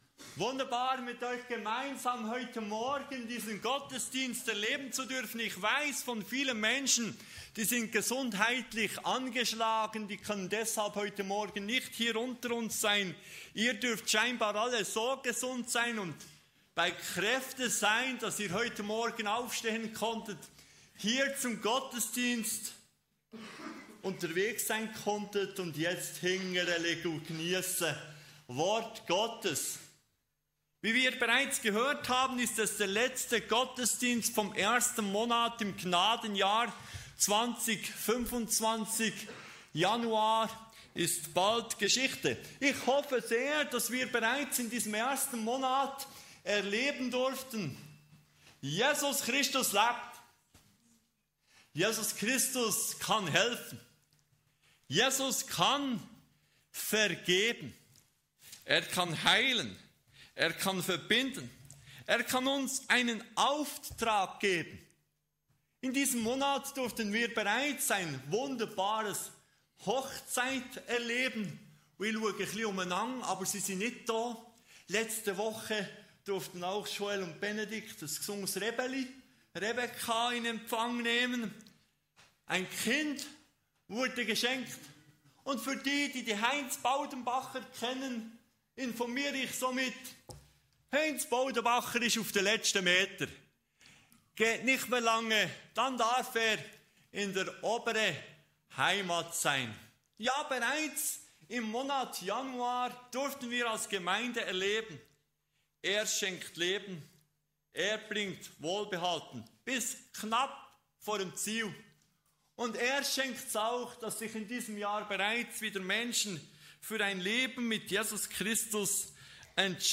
Kategorie: Gottesdienst